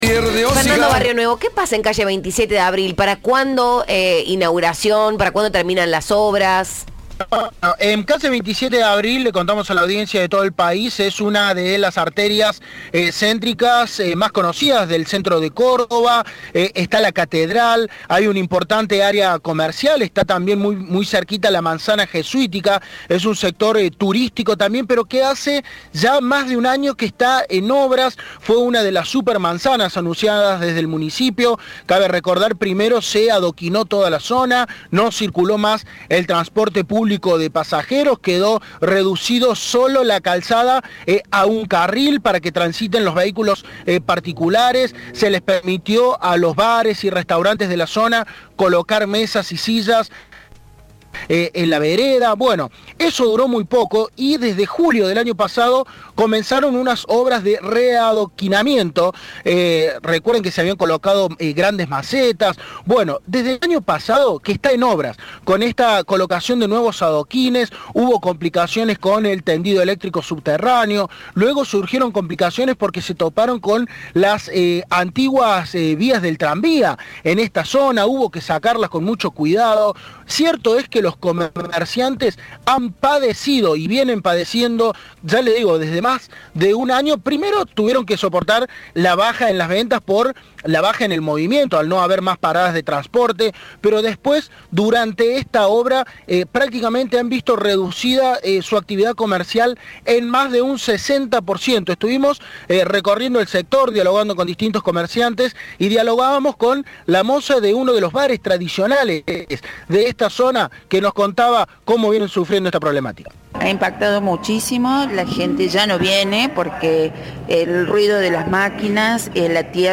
En diálogo con Cadena 3, el Secretario de Desarrollo Urbano de la Municipalidad, Diego Peralta, indicó que las obras se encuentran "en la última etapa del proyecto, que fue parte de Apertura Córdoba, un programa de cooperación internacional con el objetivo de dinamizar el área central, ecologizarla, revitalizar y recuperar el valor patrimonial del campo histórico”.